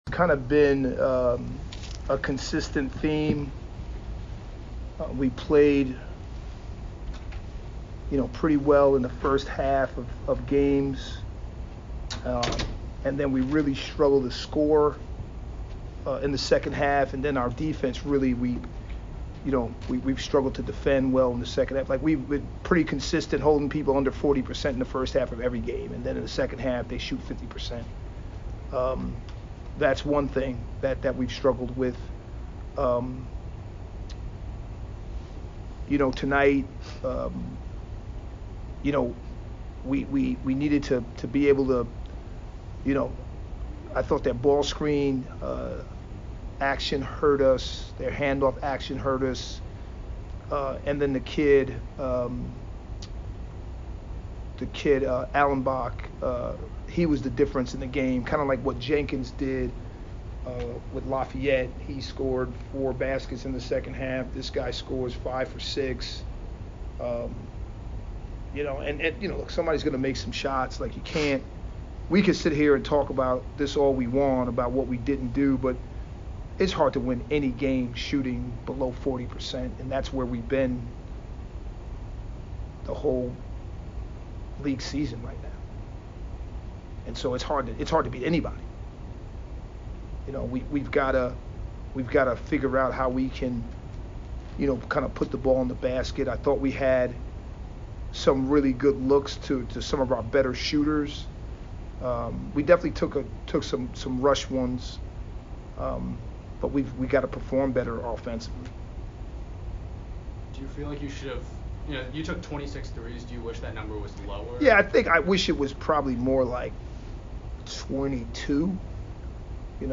Army West Point Postgame Interview